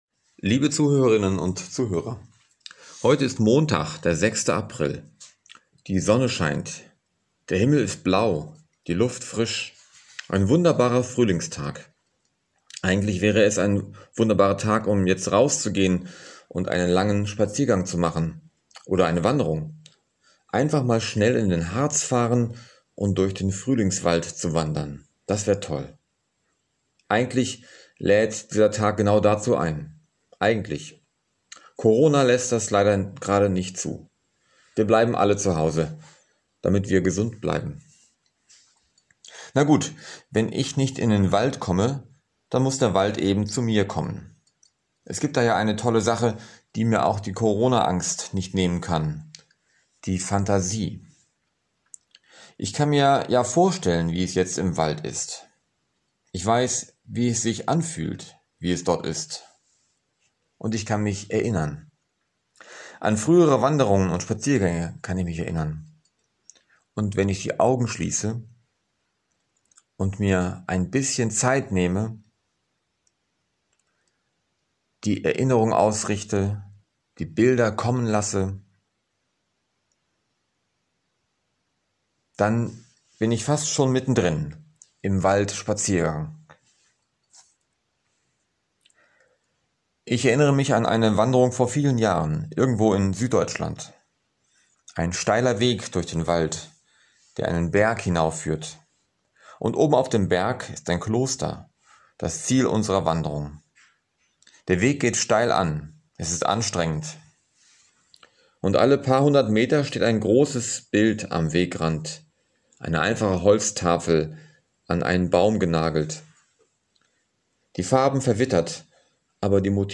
Montagsandacht